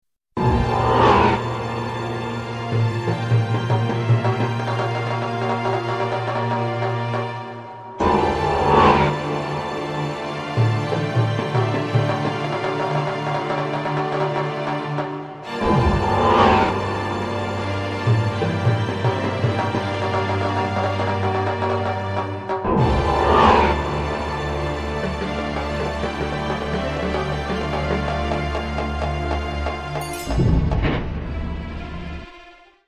Epic TV title track track in the Celtic mood